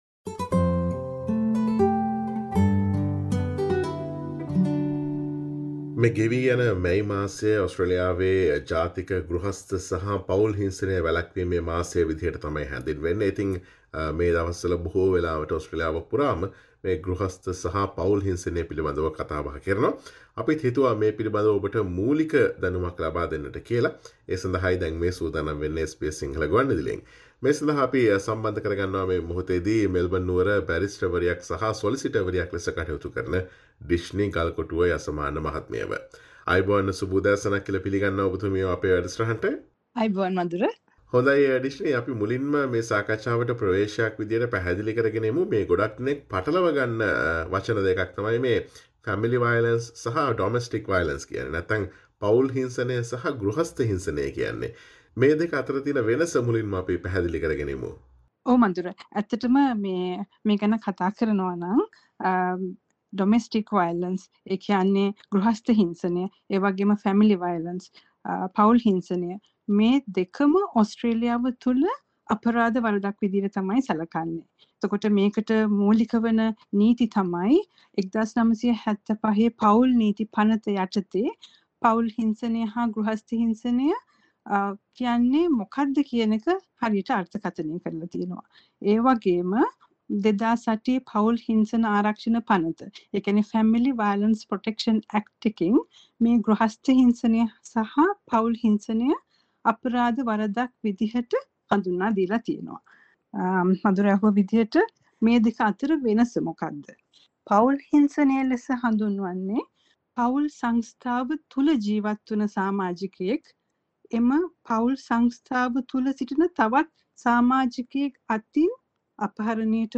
පවුල් හිංසනය සහ ගෘහස්ථ හිංසනය අතර ඇති වෙනස්කම මෙන්ම එවැනි හිංසන ඇති වීමට හෝ ඇතිවන බවට පෙරාතුව දැනුම් දෙන පූර්ව අනතුරු ඇඟවීමේ සංඥා පිළිබඳ SBS සිංහල ගුවන් විදුලිය සිදුකළ සාකච්ඡාවට සවන් දෙන්න.